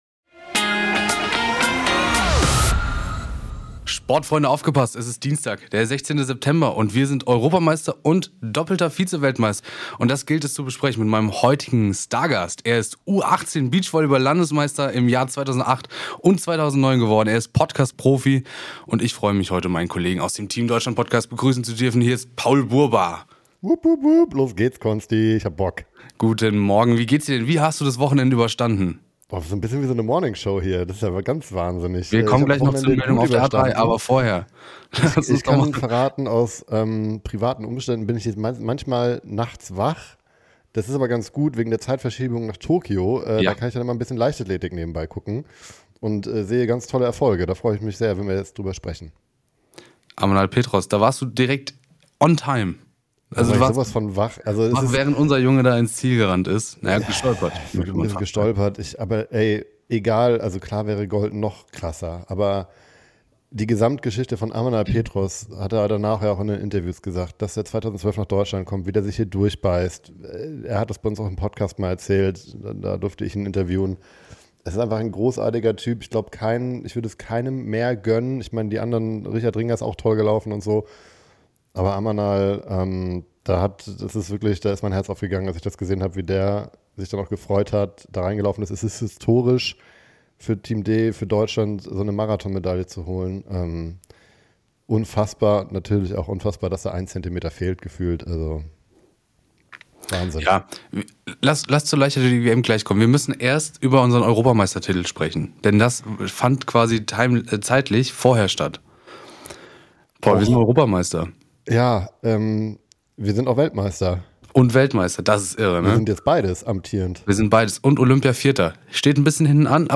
Was ein Sport-Wochenende für Deutschland und darüber sprechen wir heute im Update! Yemisi berichtet uns live von vor Ort wie ihre Vorbereitung aussah und wie die Bedingungen vor Ort sind.